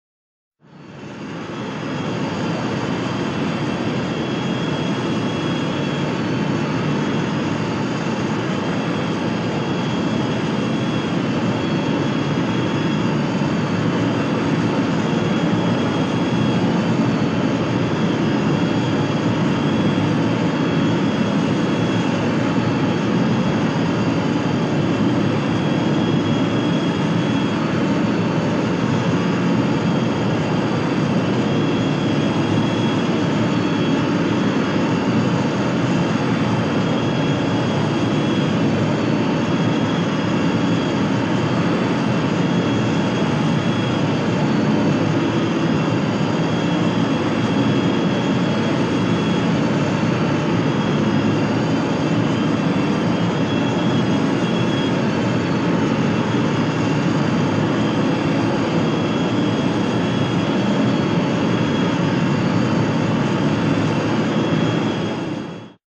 BSG FX - Viper Group - In flight
BSG_FX_-_Viper_Group_-_In_Flight.wav